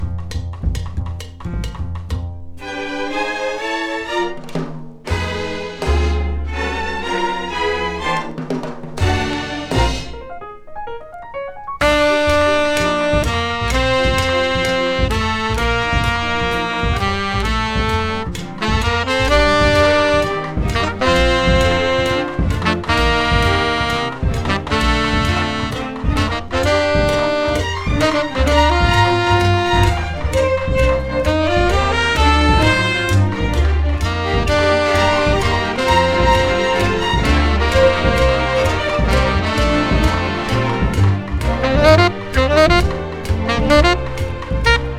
Jazz, Stage & Screen, Soundtrack　USA　12inchレコード　33rpm　Stereo